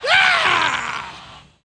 Either way, a lot of people’s heads were turned upon hearing him give some kind of wild “Yeah!” at the end of a strongly delivered sentence.
here is a better-quality sound file of just the “Yeah!” (75 KB, 16-bit at 22 KHz).